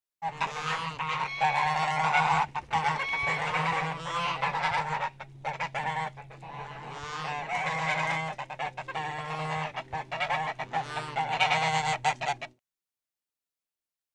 GEESE GOI02R.wav